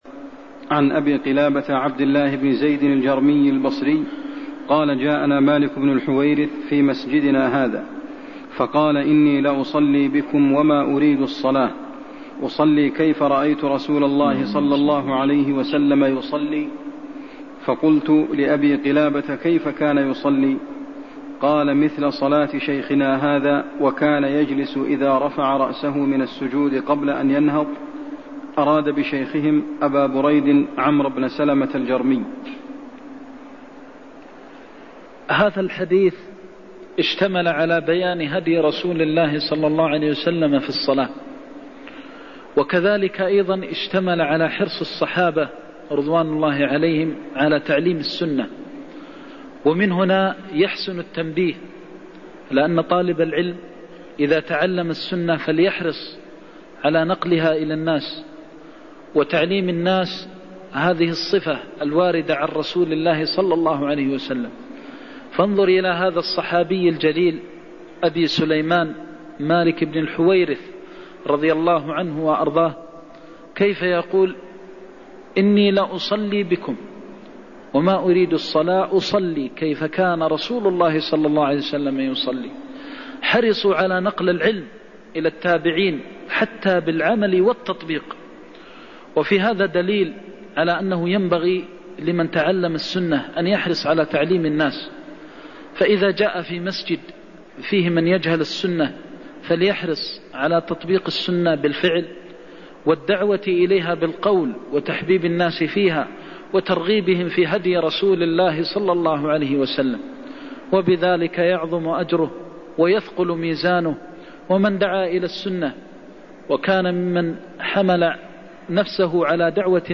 المكان: المسجد النبوي الشيخ: فضيلة الشيخ د. محمد بن محمد المختار فضيلة الشيخ د. محمد بن محمد المختار حكم جلسة الاستراحة (87)